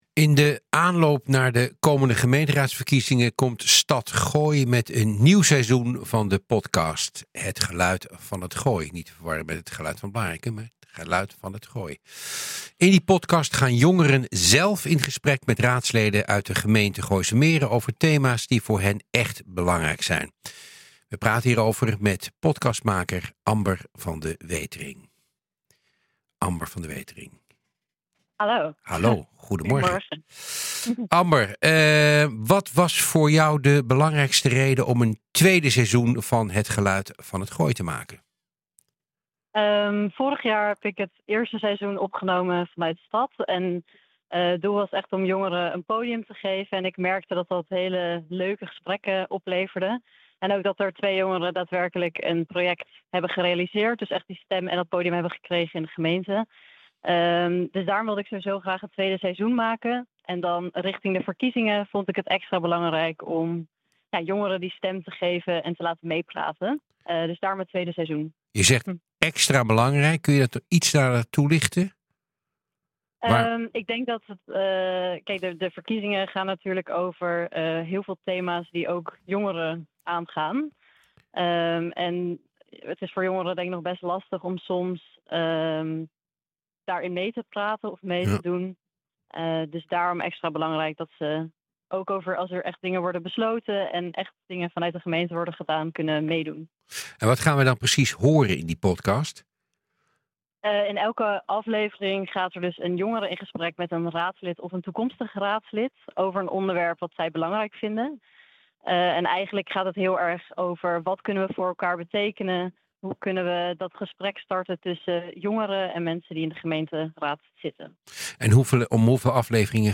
In die podcast gaan jongeren zelf in gesprek met raadsleden uit de gemeente Gooise Meren over thema’s die voor hen écht belangrijk zijn. Wij praten hierover verder met podcast-maker